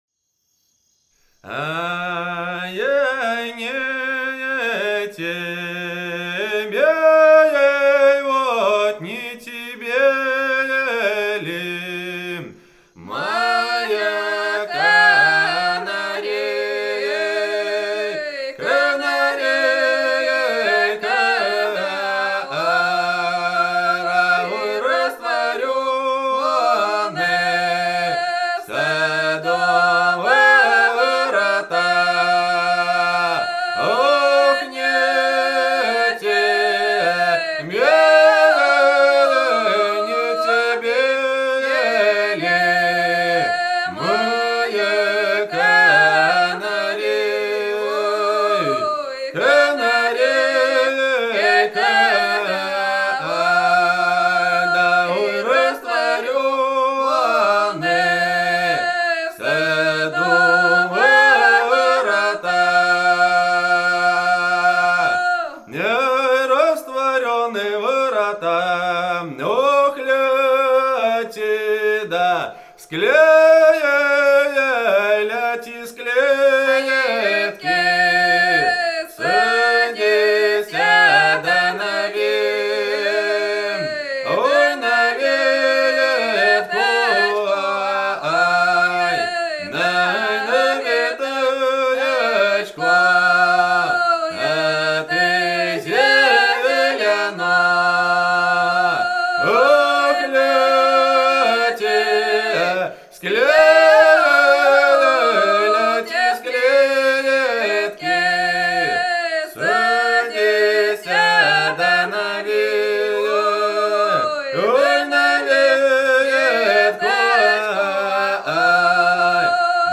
Народные казачьи песни, романсы
Волгоградская область, Кумылженский район
Старинная песня хопёрских казаков